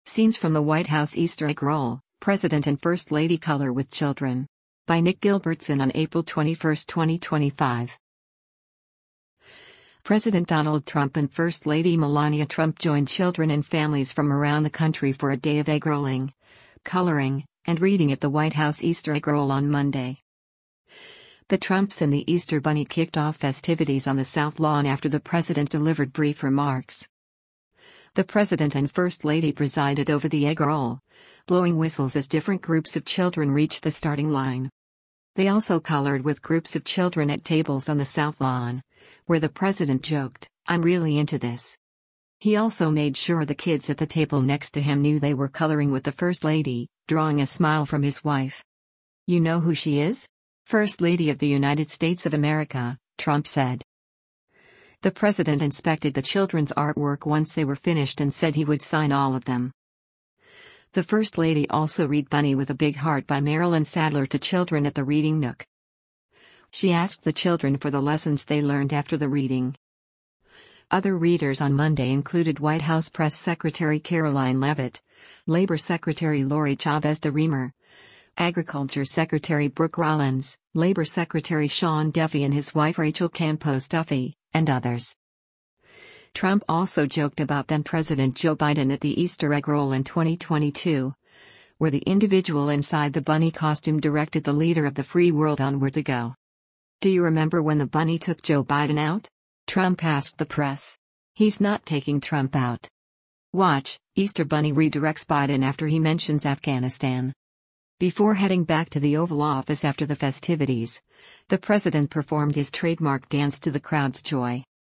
President Donald Trump and first lady Melania Trump joined children and families from around the country for a day of egg rolling, coloring, and reading at the White House Easter Egg Roll on Monday.
The president and first lady presided over the egg roll, blowing whistles as different groups of children reached the starting line.